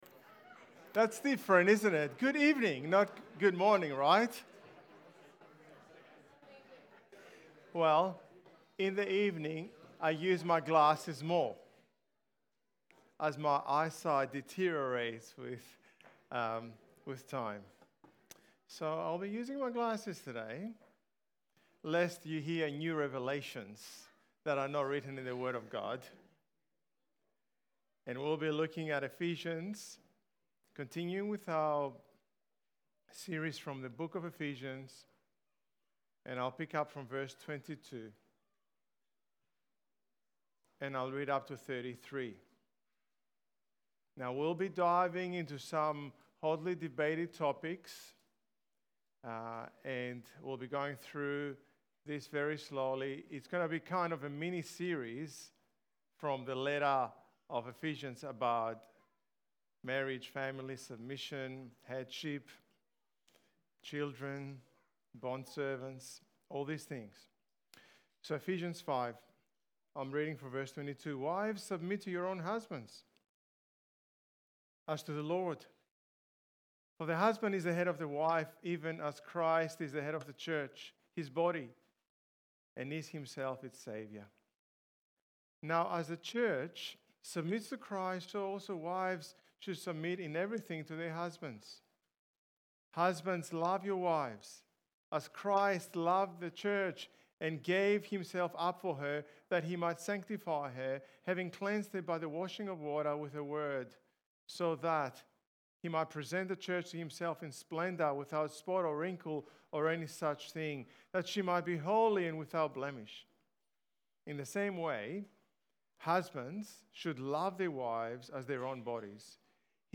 Ephesians Sermon Series